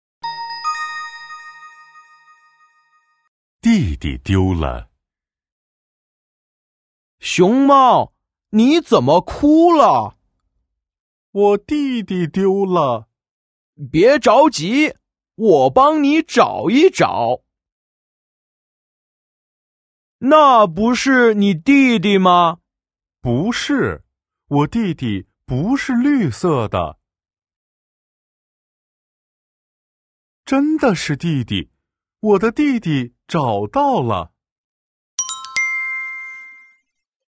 IV. Cùng hát nhé